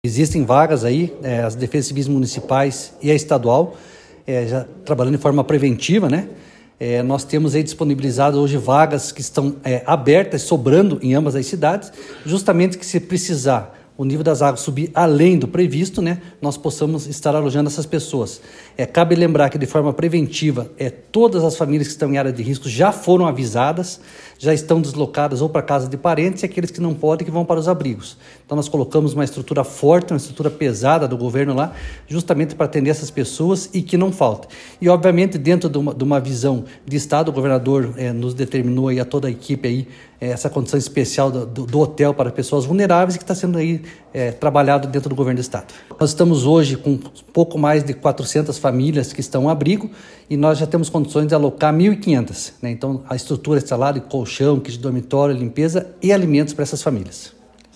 Sonora do coordenador da Defesa Civil, coronel Fernando Schunig, sobre o cuidado com os desabrigados das chuvas